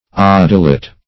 odelet - definition of odelet - synonyms, pronunciation, spelling from Free Dictionary Search Result for " odelet" : The Collaborative International Dictionary of English v.0.48: Odelet \Ode"let\, n. A little or short ode.